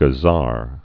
(gə-zär)